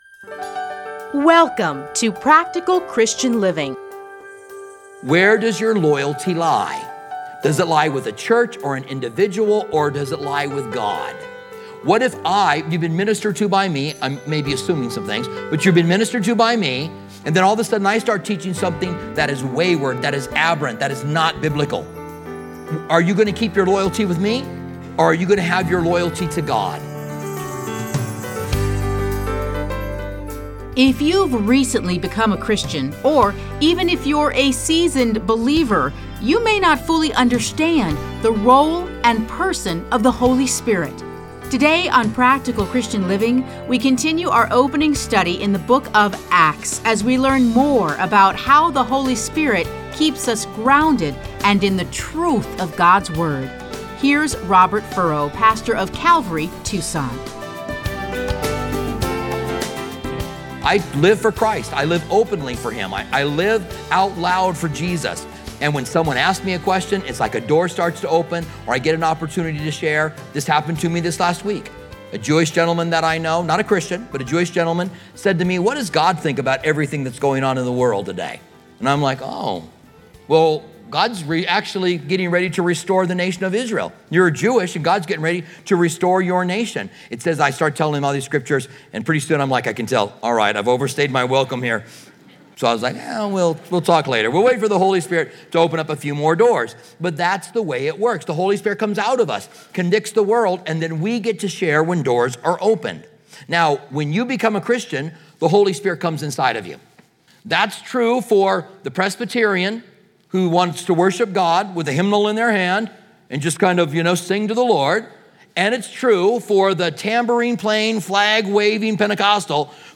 Listen to a teaching from Acts 1:1-11.